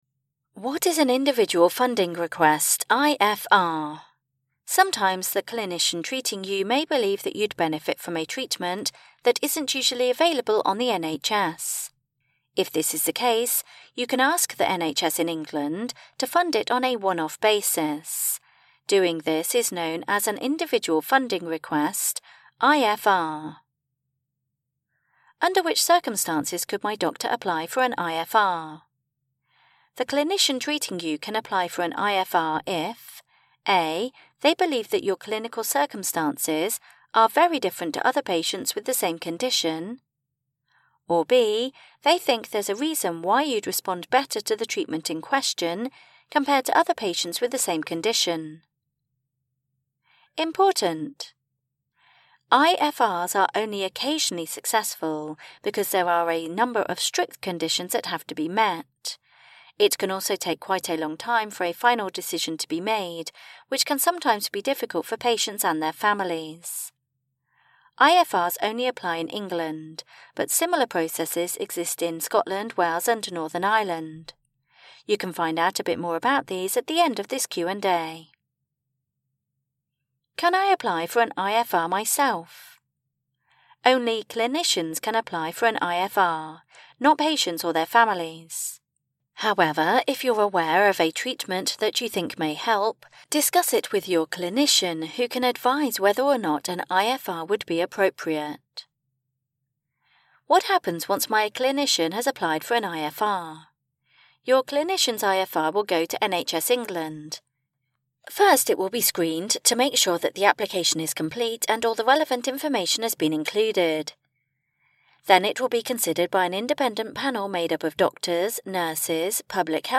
Audio version of Anthony Nolan's patient information: What is an Individual Funding Request (IFR)?